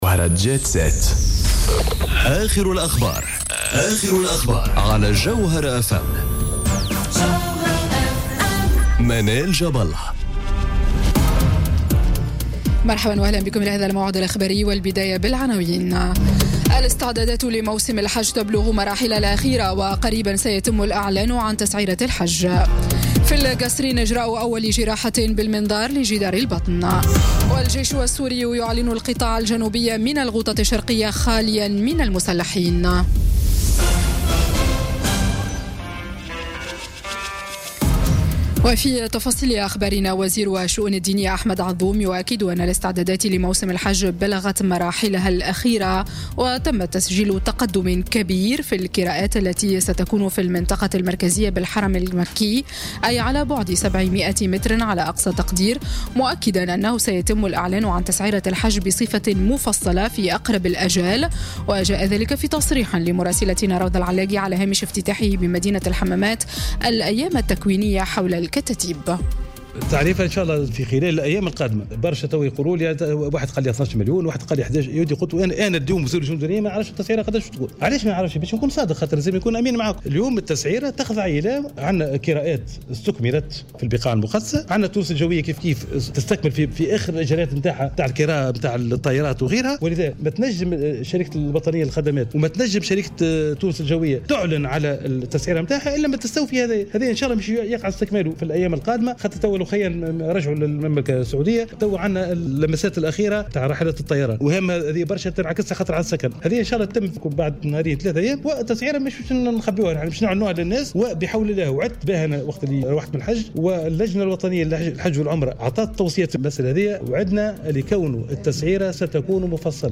نشرة أخبار السابعة مساء ليوم السبت 31 مارس 2018